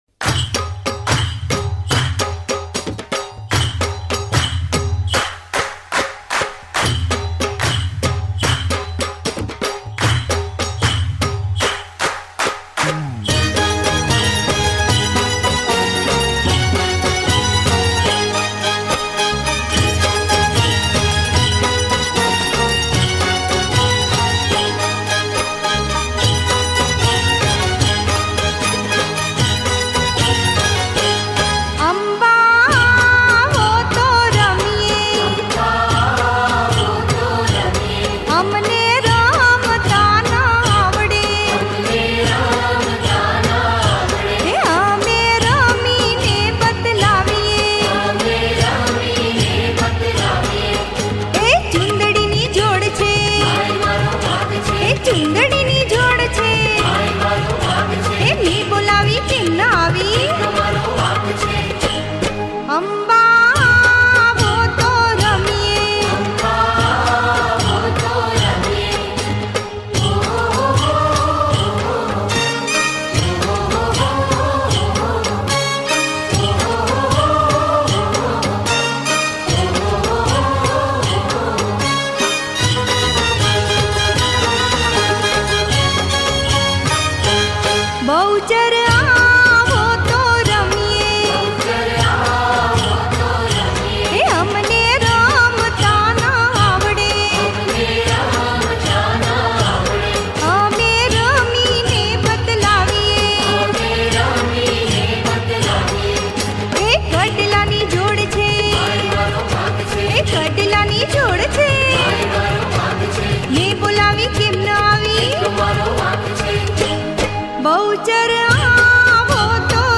ગીત સંગીત ગરબા - Garba
Mataji No Garbo